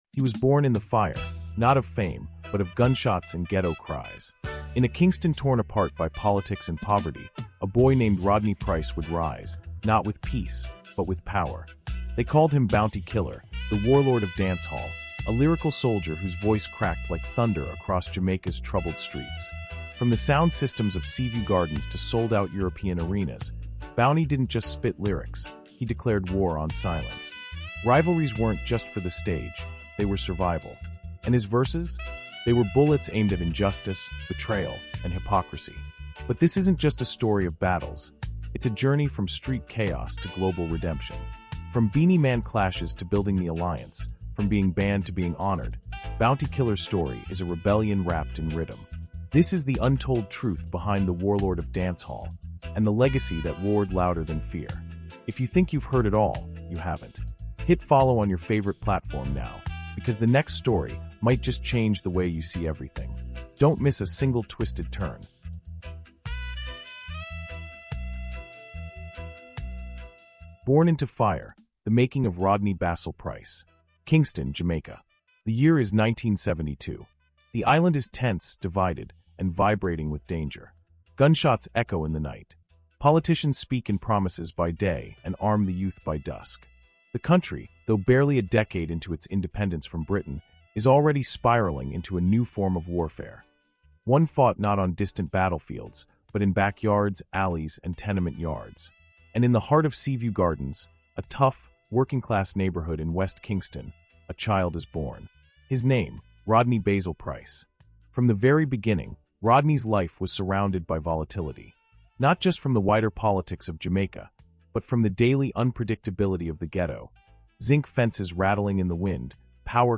CARIBBEAN HISTORY: Bounty Killer — The Warlord of Dancehall is a cinematic Caribbean history audiobook documentary exploring the untold story of Rodney Basil Price, better known as Bounty Killer — a pioneer of Jamaican music and a fierce voice in Caribbean music culture. Born in the fire of Kingston’s political violence, baptized by a gunshot at 14, Bounty Killer carved a legendary path through lyrical warfare, cultural rebellion, and black history.